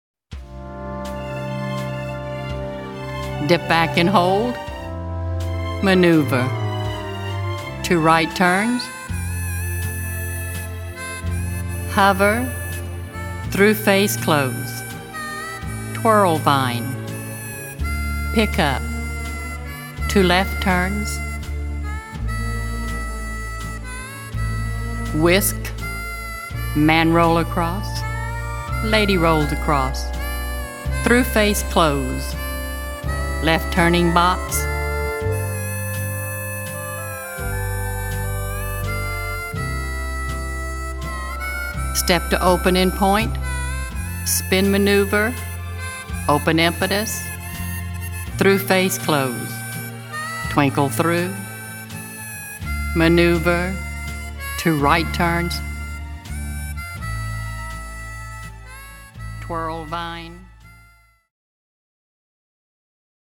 Cued Sample
Waltz, Phase 3